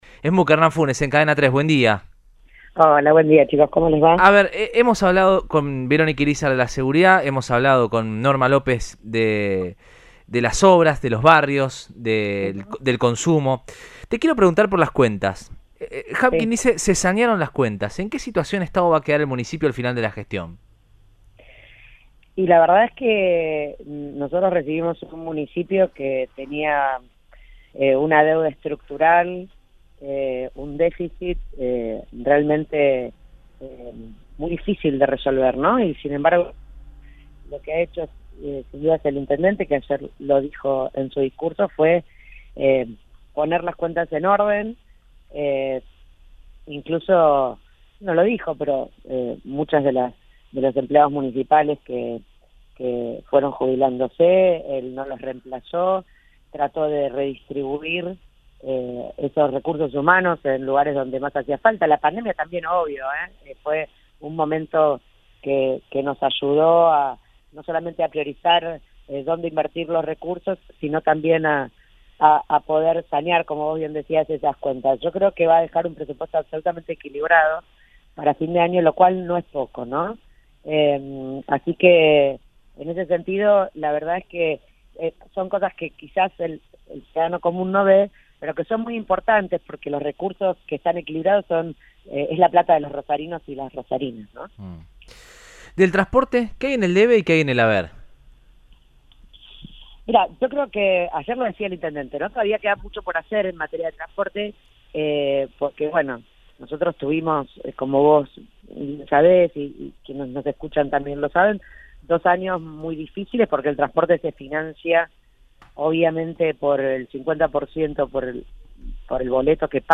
Luego de la apertura de las Sesiones Ordinarias del Concejo Municipal de Rosario por parte del intendente Pablo Javkin, la presidenta del cuerpo, la radical María Eugenia Schmuck resaltó la gestión económica del municipio en diálogo con Radioinforme 3 en Cadena 3 Rosario.